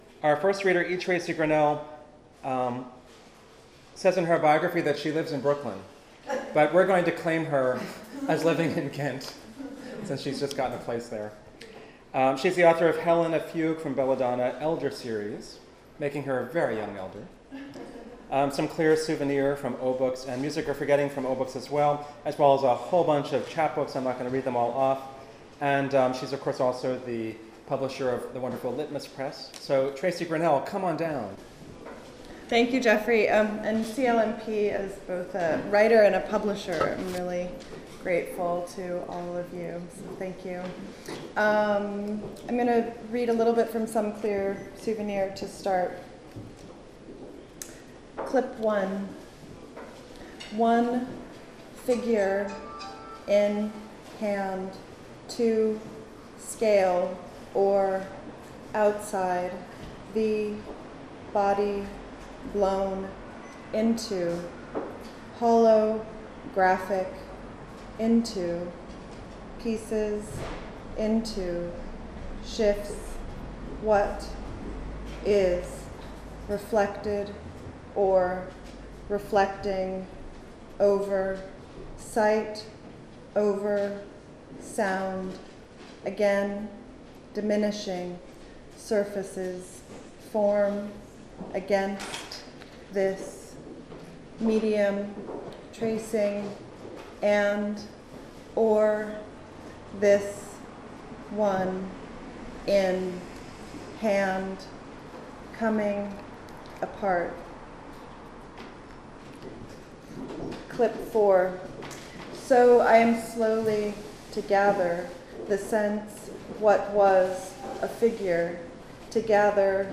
7th Annual Hudson Valley Literary Festival: May 19, 2012: 11am- 4pm
Hudson Hall at the Historic Hudson Opera House